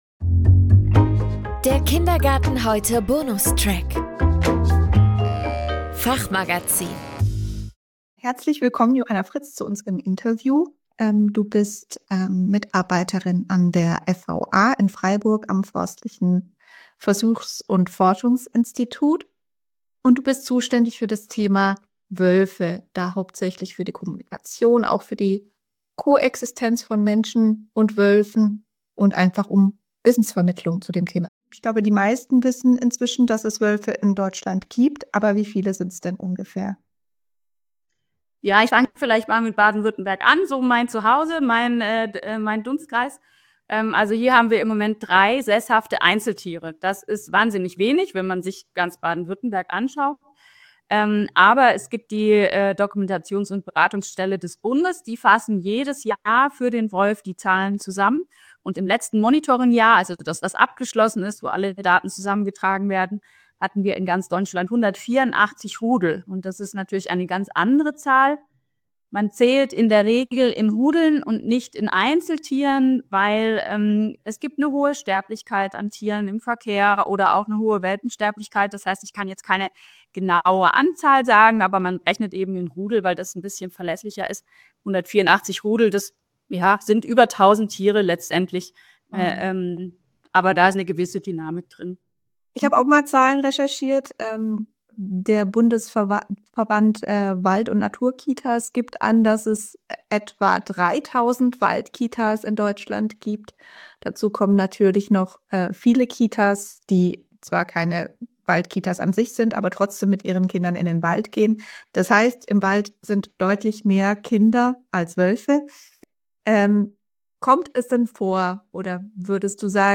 Hören Sie hier einen spannenden Zusammenschnitt - das gesamte Interview können Sie in kindergarten heute 1_25 nachlesen.